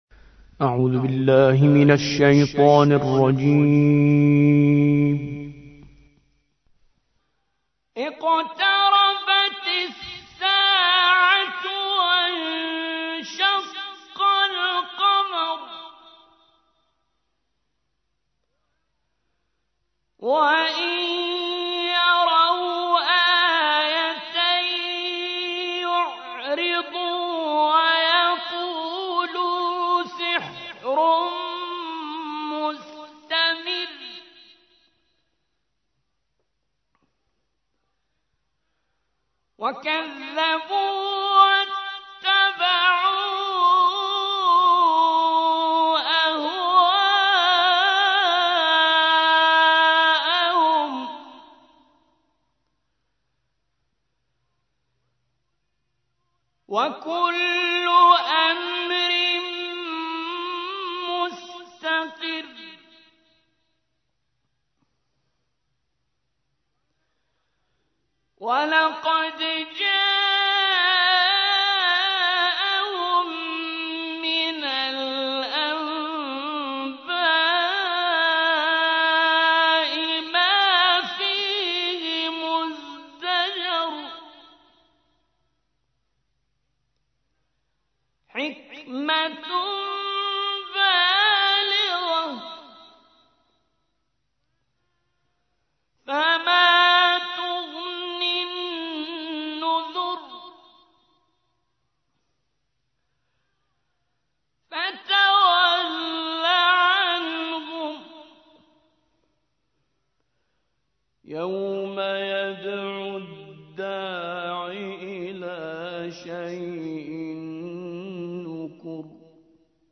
54. سورة القمر / القارئ